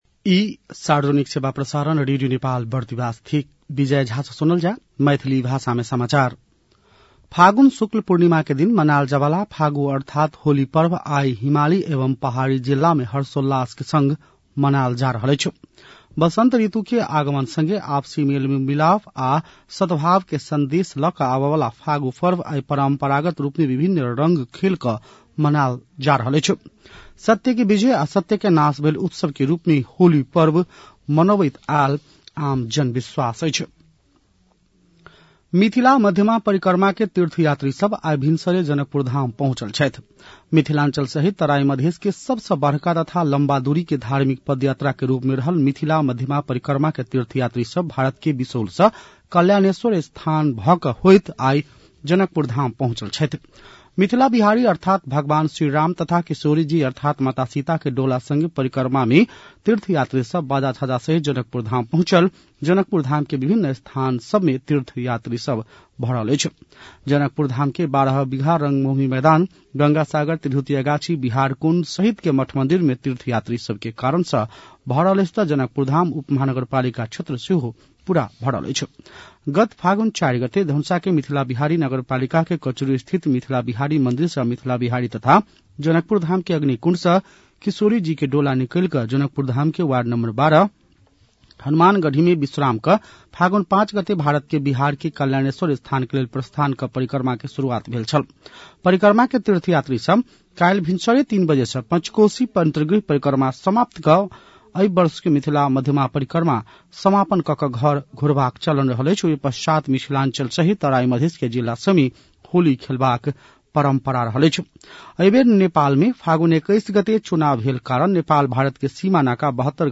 An online outlet of Nepal's national radio broadcaster
मैथिली भाषामा समाचार : १८ फागुन , २०८२